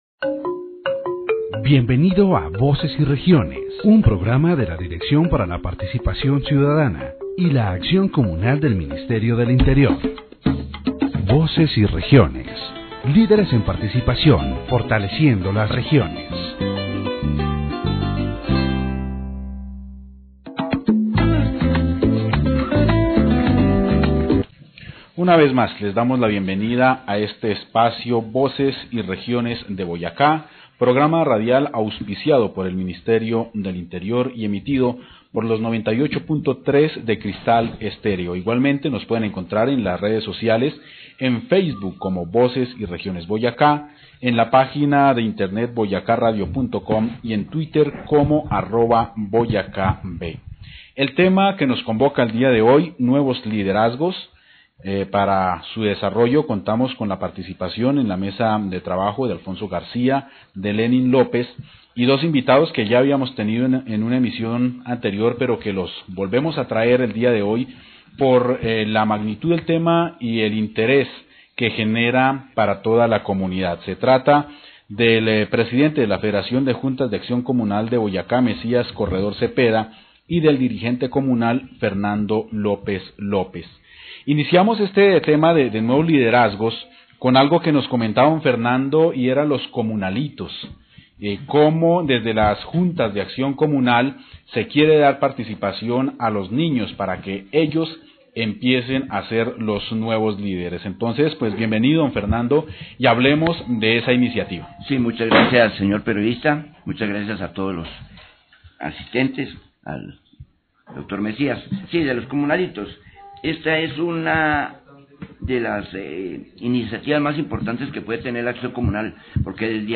The radio program "Voses y Regiones" presents an optimistic vision about the future of community leadership in Colombia.